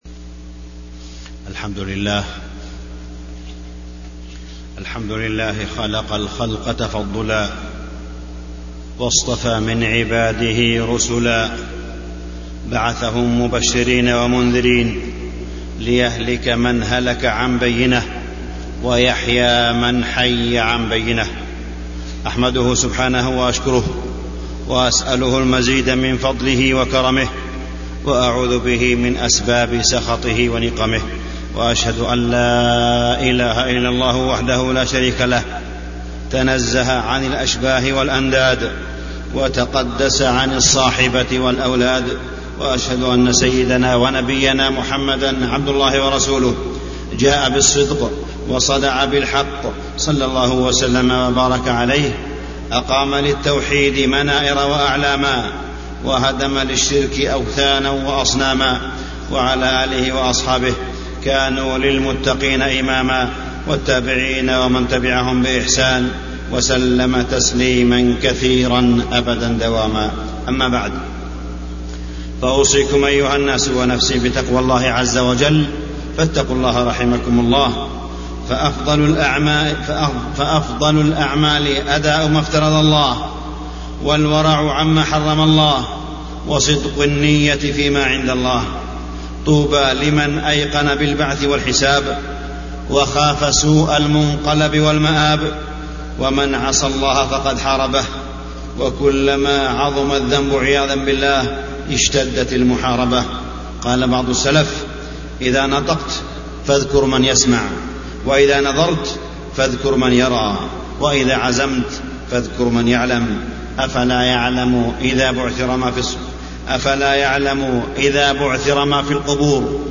تاريخ النشر ٢١ شعبان ١٤٢٩ هـ المكان: المسجد الحرام الشيخ: معالي الشيخ أ.د. صالح بن عبدالله بن حميد معالي الشيخ أ.د. صالح بن عبدالله بن حميد التعامل الأسري